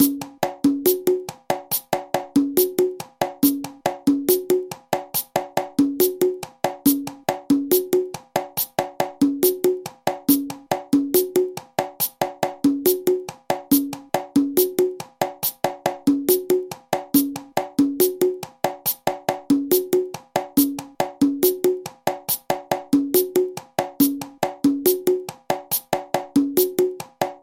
New-York Mozambique (congas lent)
new_york_mozambique_conga_lent.mp3